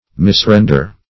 Misrender \Mis*ren"der\, v. t.